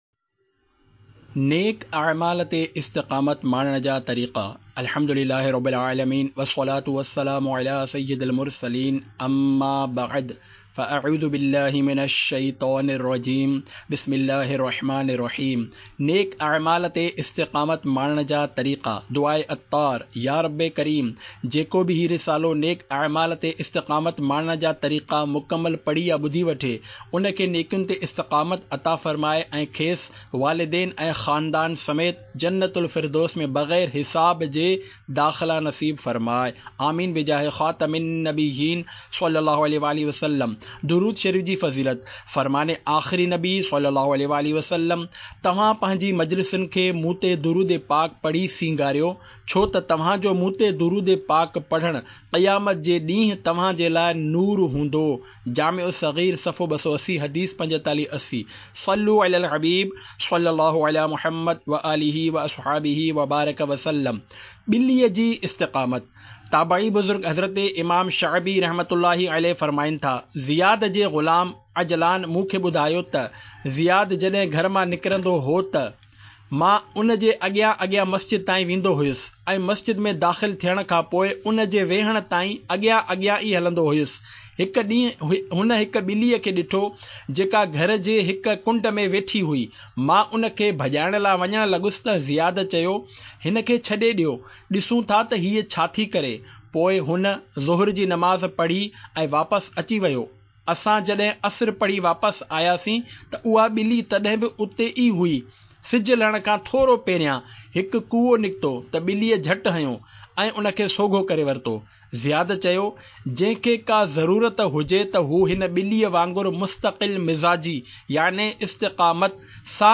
Audiobook - Naik Amaal Par Istiqamat Pane Ke Tariqay (Sindhi)